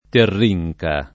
[ terr &j ka ]